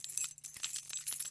描述：不同方向和持续时间的钥匙被摇动的声音。
标签： 叮当作响 钥匙 金属 马刺
声道立体声